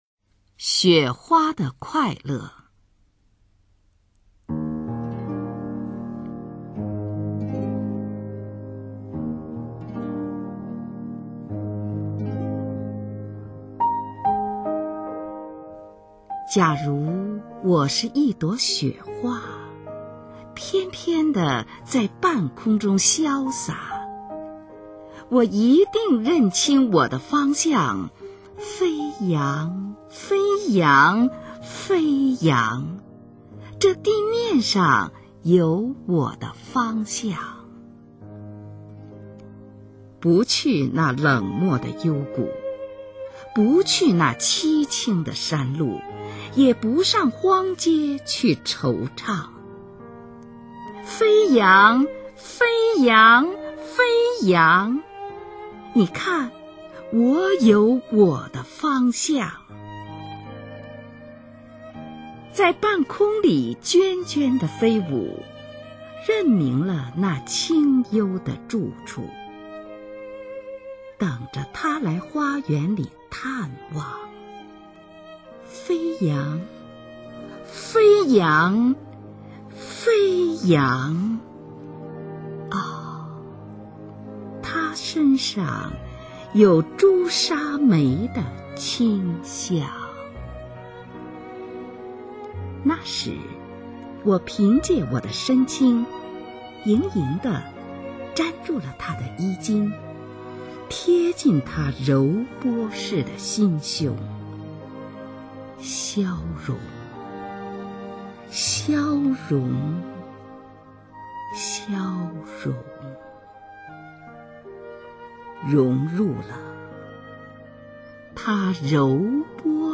首页 视听 经典朗诵欣赏 中国百年经典诗文配乐诵读：光与色、情与景、人与事